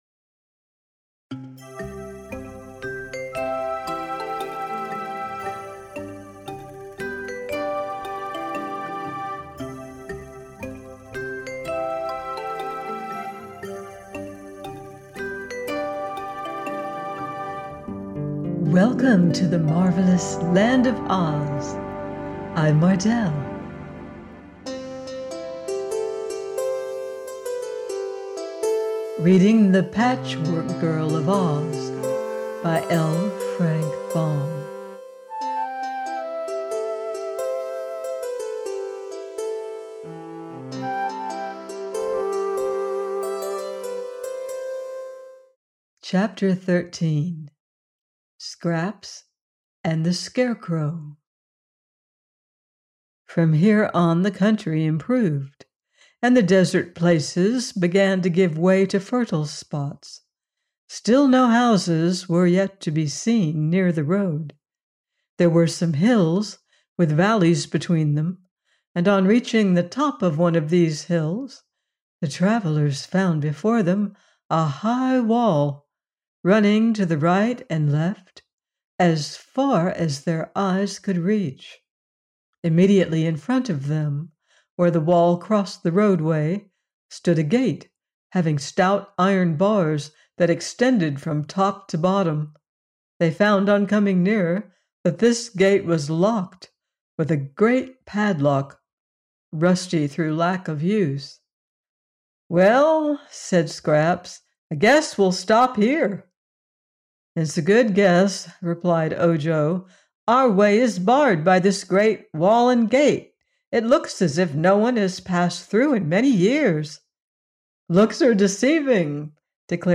The Patchwork Girl of Oz – by L. Frank Baum - audiobook